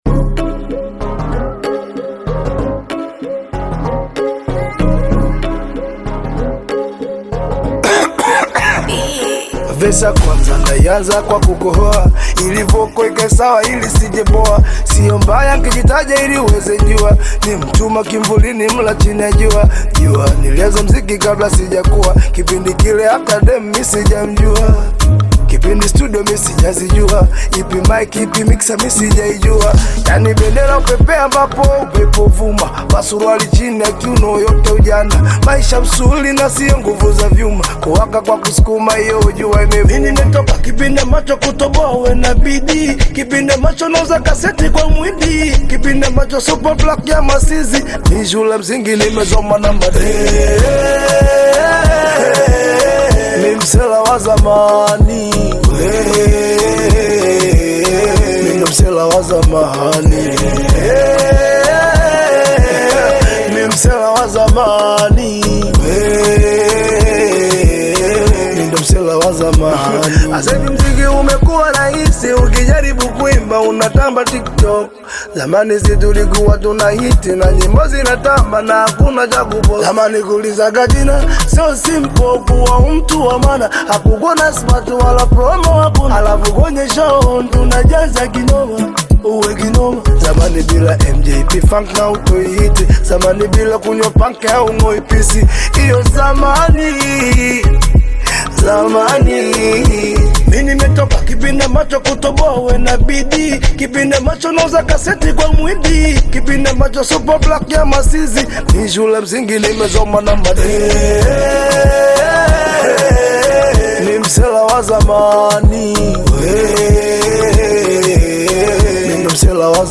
Audio Bongo flava Latest